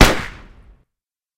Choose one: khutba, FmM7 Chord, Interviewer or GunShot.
GunShot